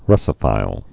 (rŭsə-fīl)